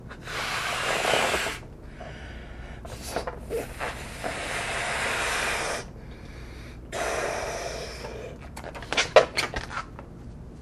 blowingupballon2
ballon inflation sound effect free sound royalty free Memes